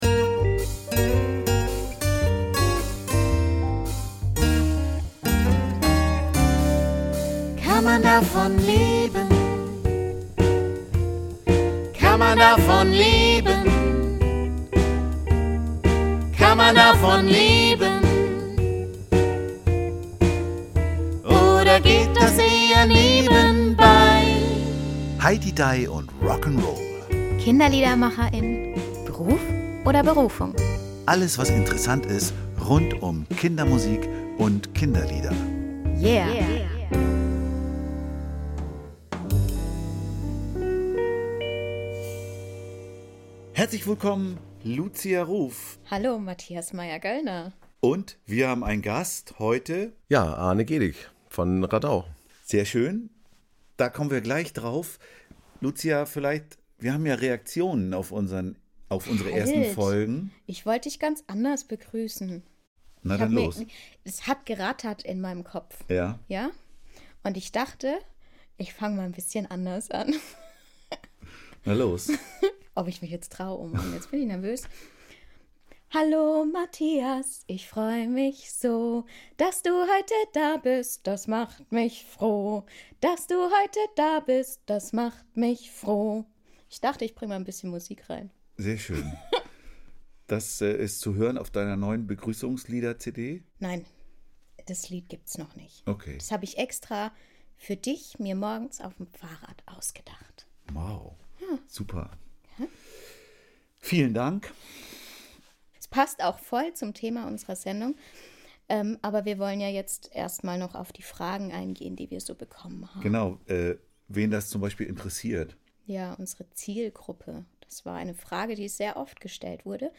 Und warum er von Queen "Spread your wings" besser findet als "Bohemian Rhapsody" - auch das verrät er uns im Gespräch.